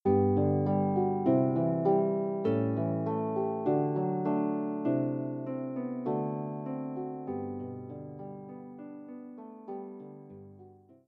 traditional Welsh tunes
solo lever or pedal harp